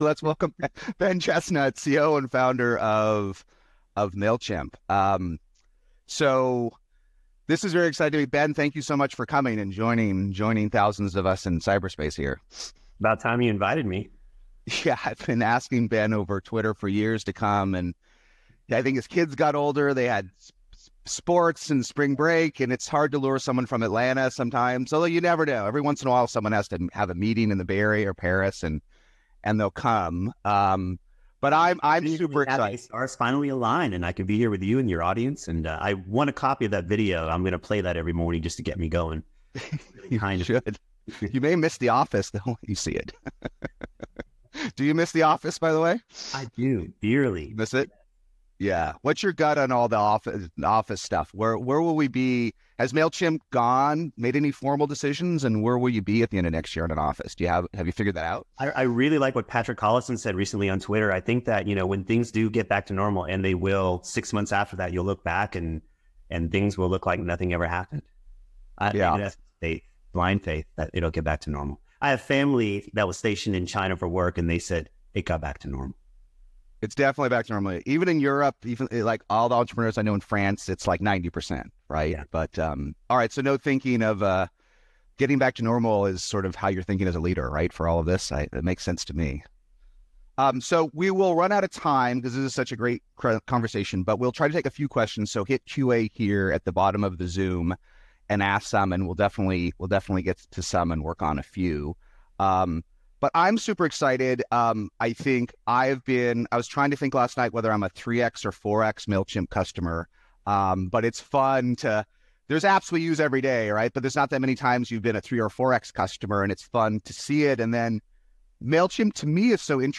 At SaaStr Annual 2020, for the first time, we were able to get Ben Chestnut CEO of Mailchimp to join us for a very open and honest discussion of the top challenges really building the #1 100% bootstrapped SaaS of all time.